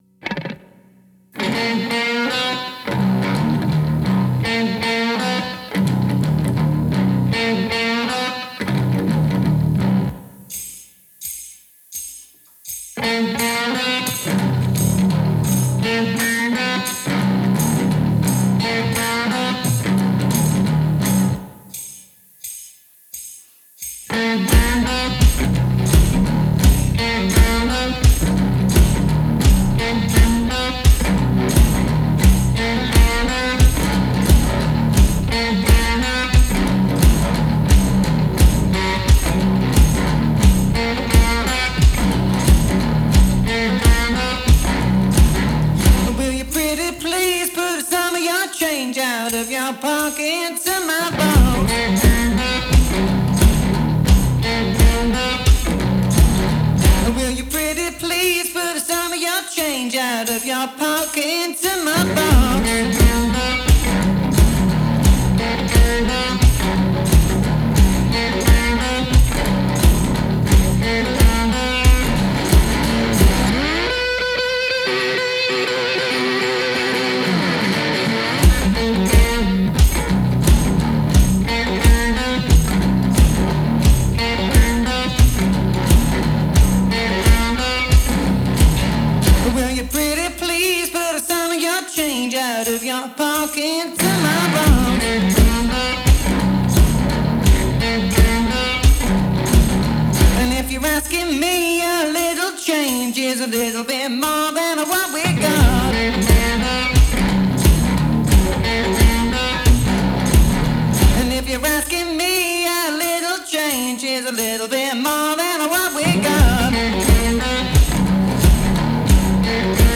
He’s one guy, a street performer, with videos.